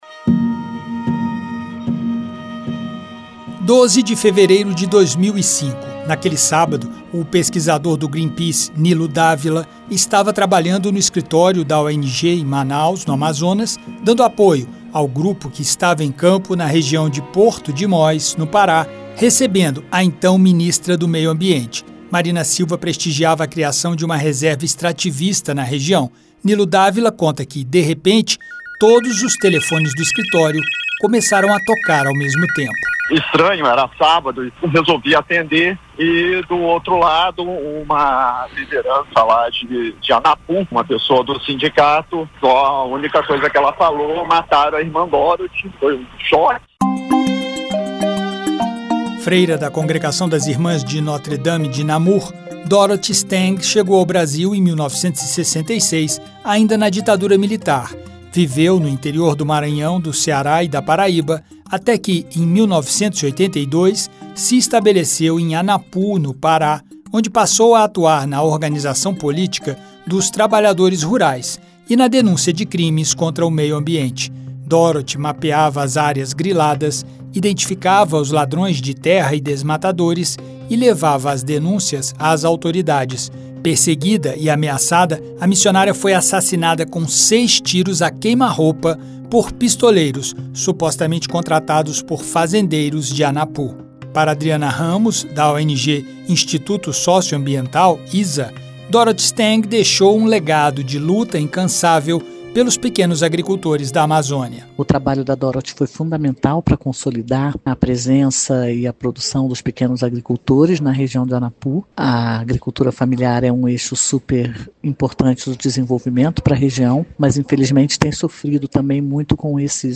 Seu legado na defesa da Floresta Amazônica e dos direitos dos pequenos agricultores segue vivo, sendo lembrado por ambientalistas e autoridades. O senador Rogério Carvalho (SE), líder do PT no Senado, destacou que lembrar de Dorothy é reafirmar a luta pelos direitos sociais dos mais desfavorecidos.